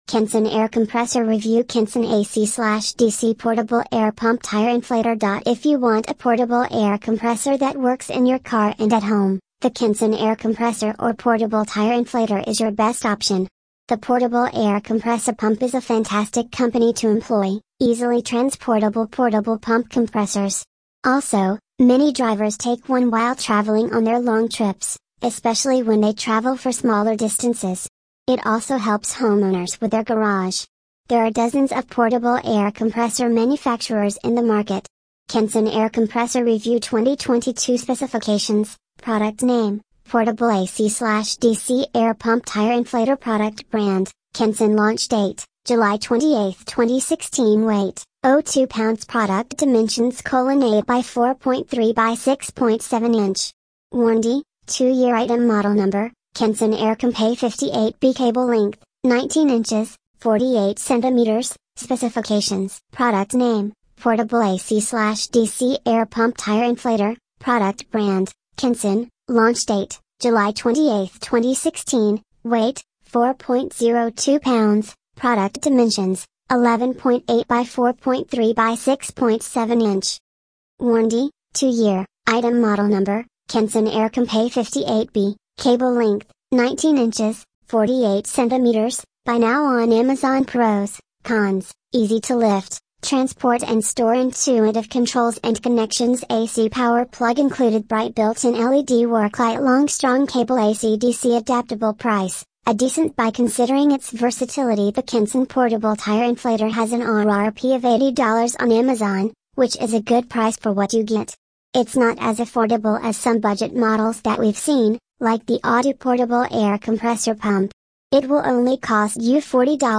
Tags: air compressor compressor